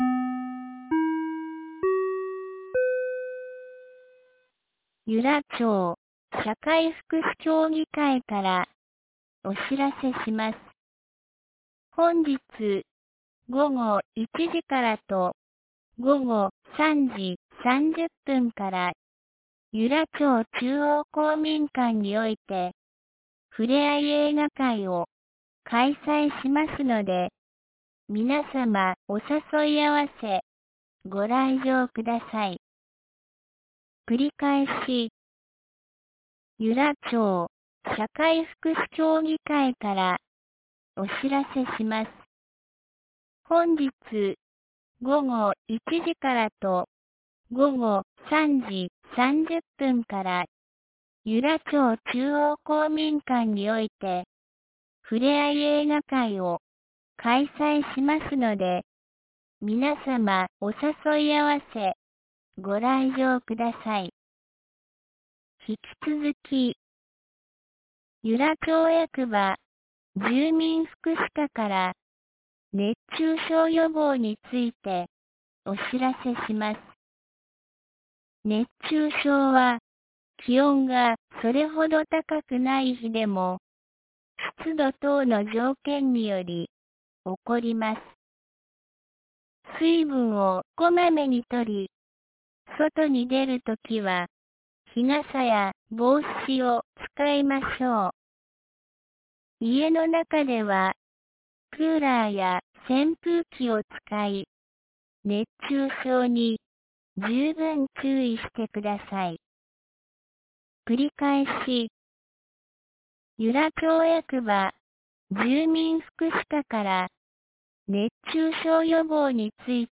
2019年08月10日 12時22分に、由良町より全地区へ放送がありました。
放送音声